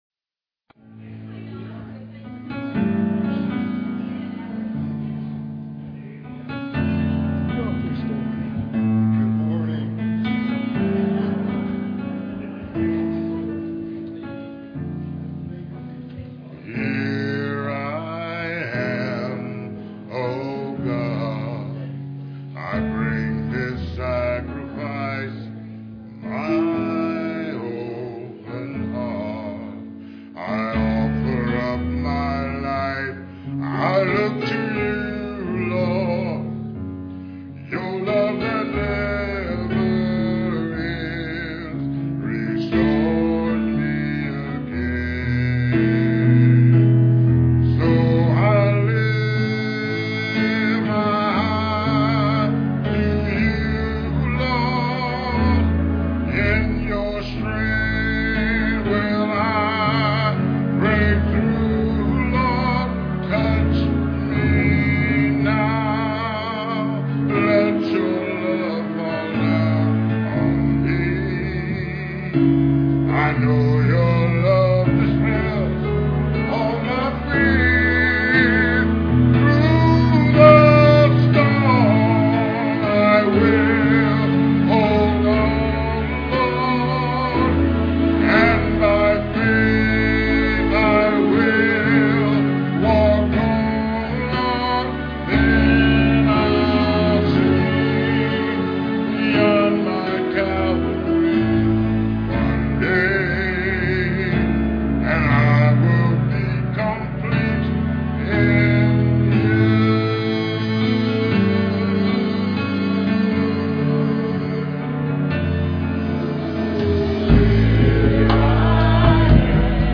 Solo
Piano and organ duet